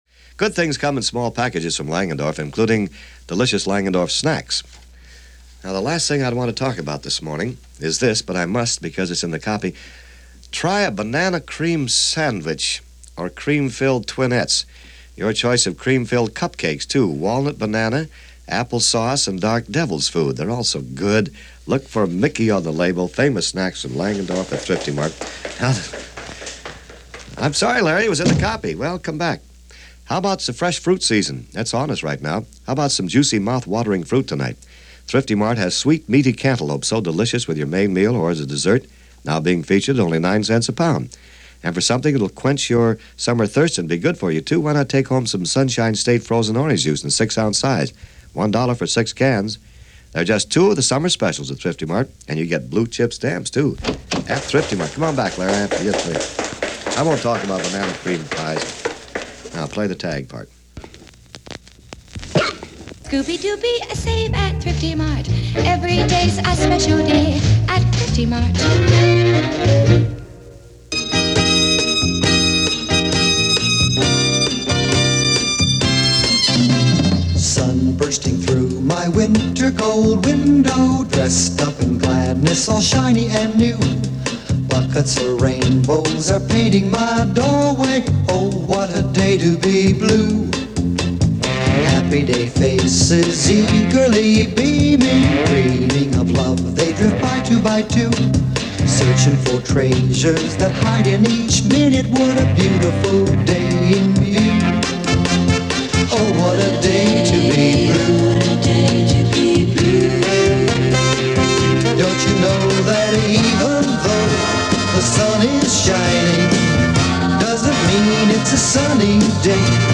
No. Although, in an effort to attempt a modicum of hip, radio stations like KMPC (known as The Station of The Stars), were bastions of Sunshine Pop and certain records which crossed-over to Top 40.
Maybe the music was strange, but the ads were the same (minus the acne ads for Top-40) – places which no longer exist today and products which have since disappeared from shelves long ago.